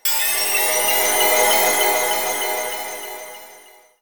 magic21.wav